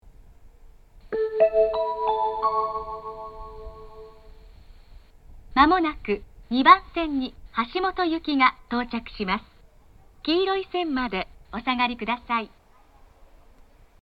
東海道型（女性）
接近放送
橋本行の接近放送です。
接近放送は1回のみ放送されます。